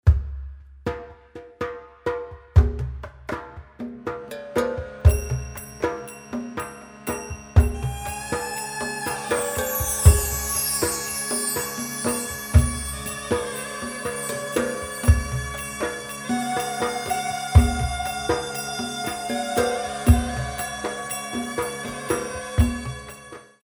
five beats